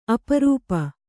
♪ aparūpa